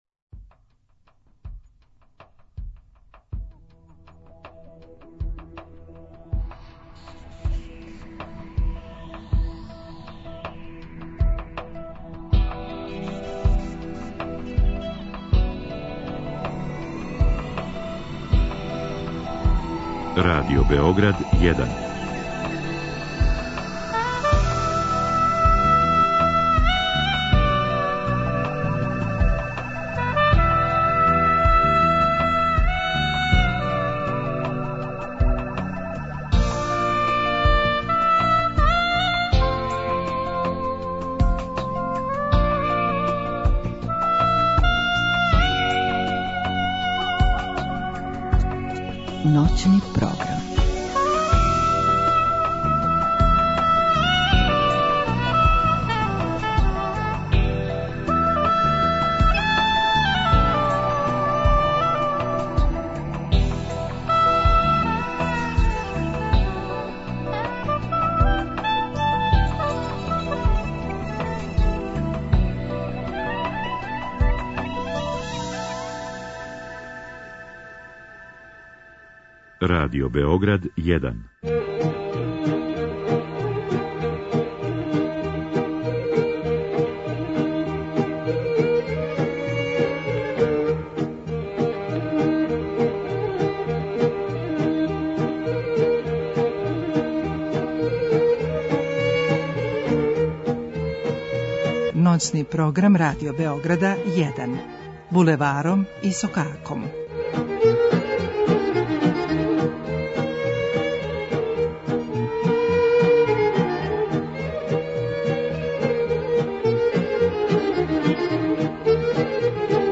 Свираће вам фрулаши,виолинисти,трубачи. Биће и погађалице,укључења...Све оно што очекујете...углавном.